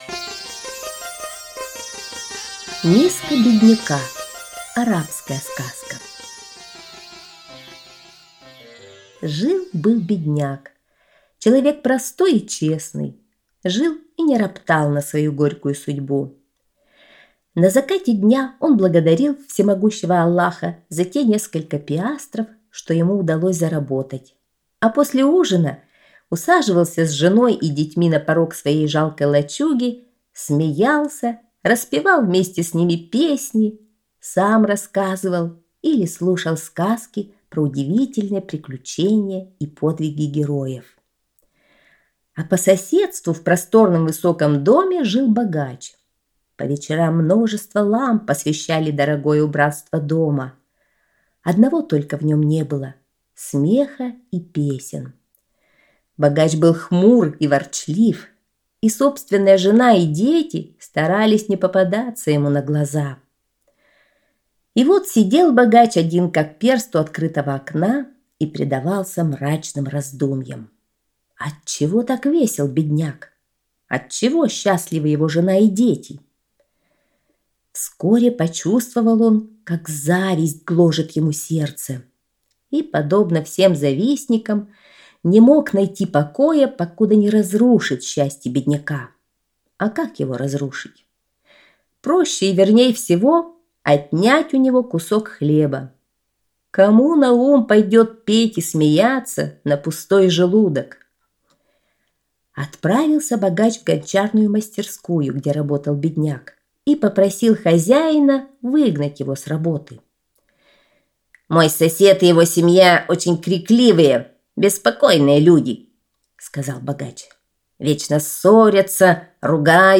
Миска бедняка - арабская аудиосказка - слушать онлайн